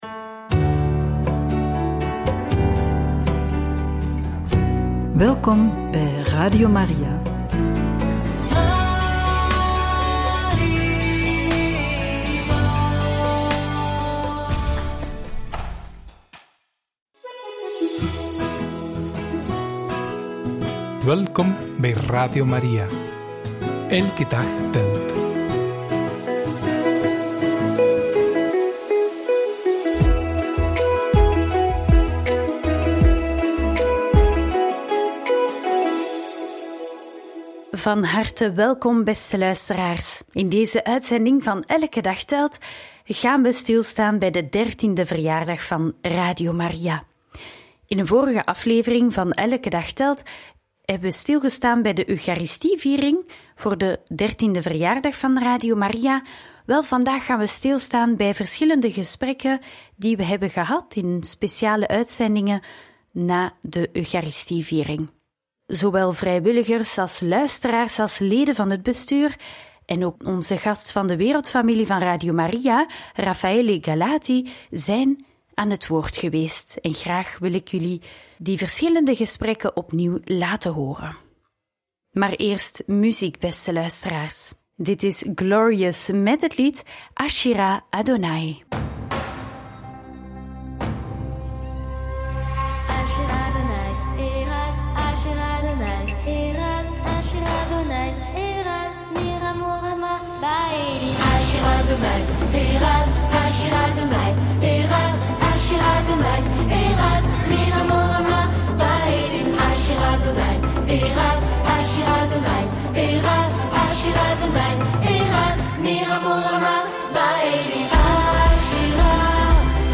In gesprek met vrijwilligers tijdens de verjaardag en twee fragmenten uit feestelijke uitzendingen!
in-gesprek-met-vrijwilligers-tijdens-de-verjaardag-en-twee-fragmenten-uit-feestelijke-uitzendingen.mp3